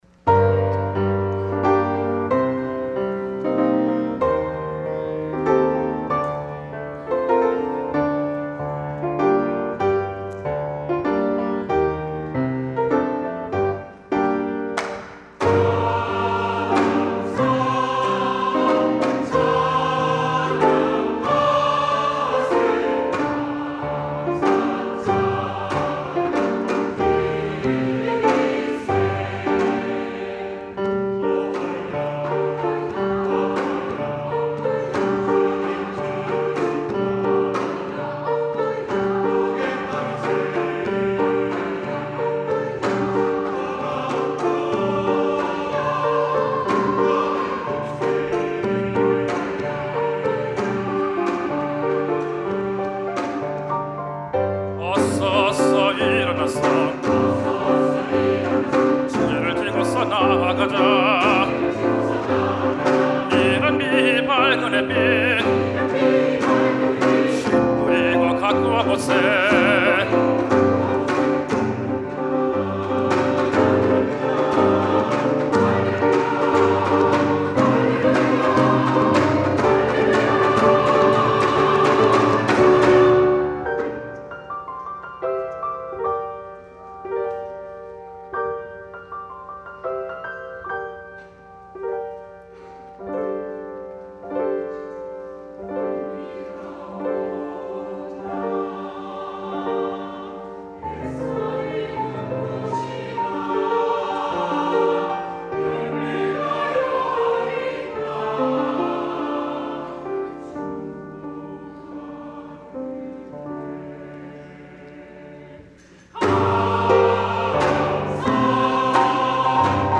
찬양 :: 141102 할렐루야 주께 감사하라
" 할렐루야 주께 감사하라 "- 시온 찬양대